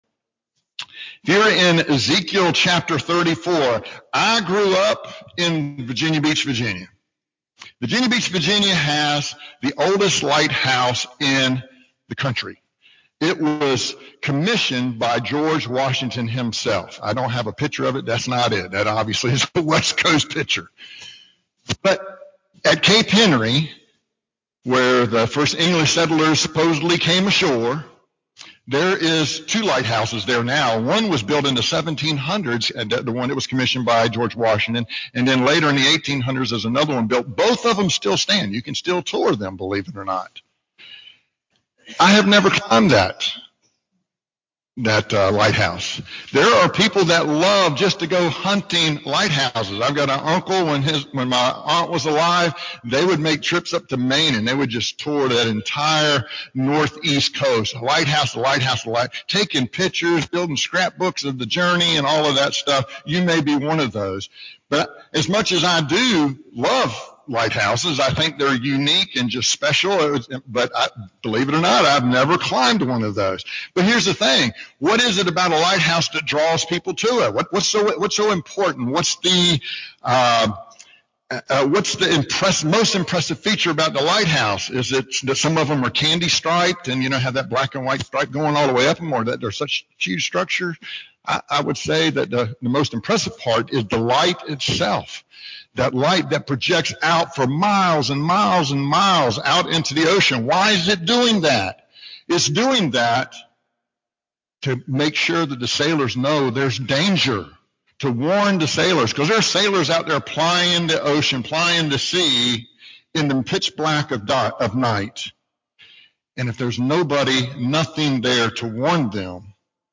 Sunday morning: The Rescue from the Dark Ezekiel 34:11-31